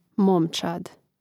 mȍmčād momčad